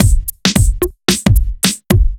OTG_Kit 2_HeavySwing_110-D.wav